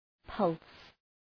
Προφορά
{pʌls}
pulse.mp3